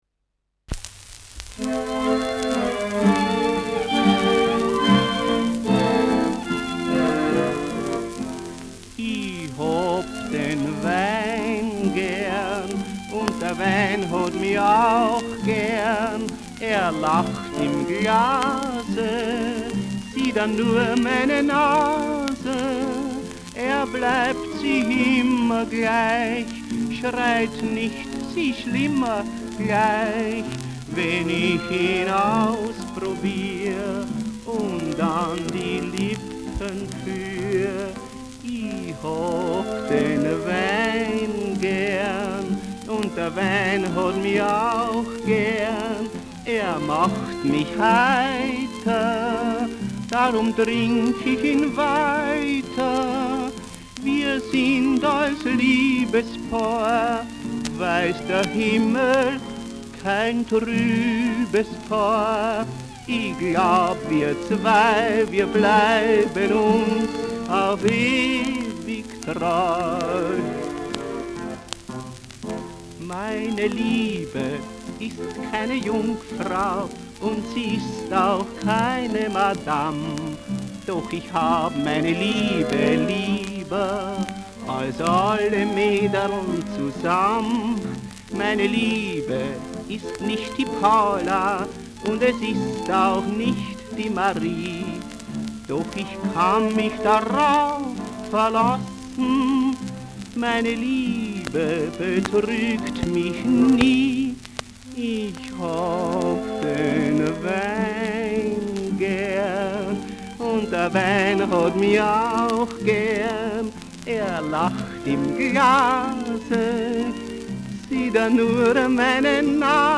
Schellackplatte
[Berlin] (Aufnahmeort)
Operettenmelodie* FVS-00011